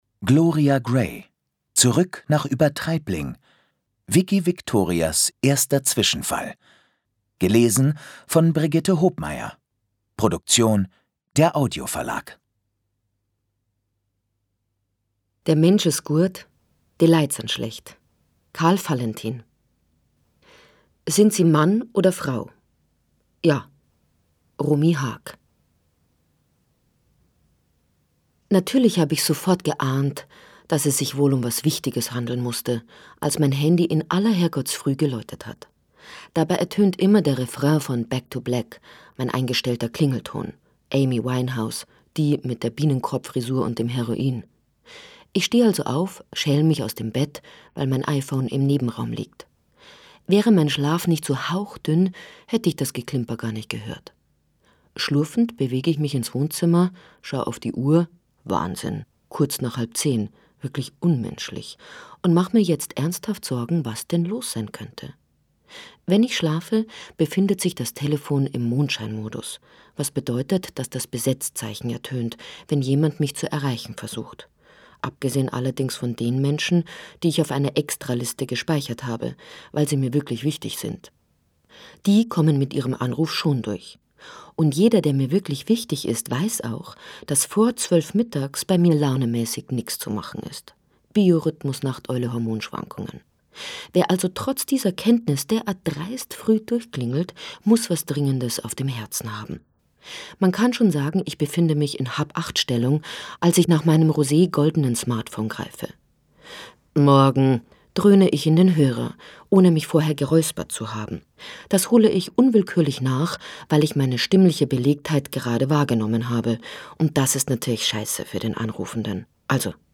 Hörbuch: Zurück nach Übertreibling.
Zurück nach Übertreibling. Vikki Victorias erster Zwischenfall Lesung mit Brigitte Hobmeier
Brigitte Hobmeier (Sprecher)